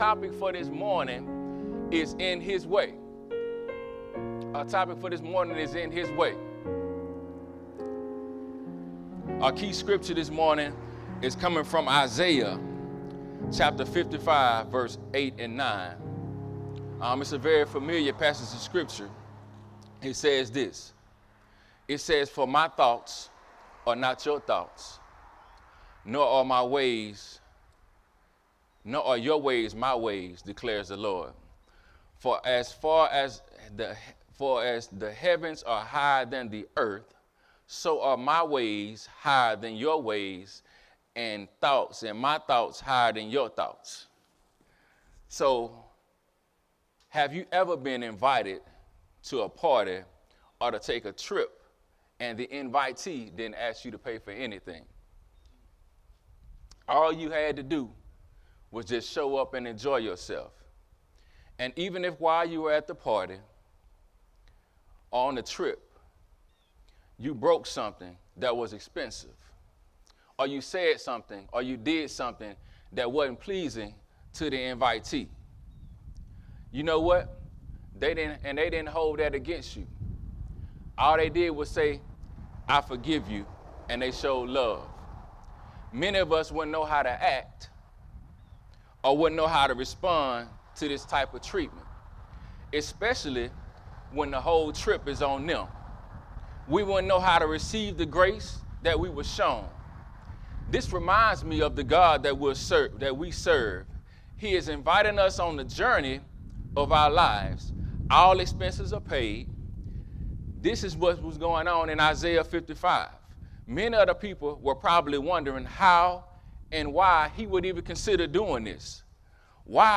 A message from the series "In His Way."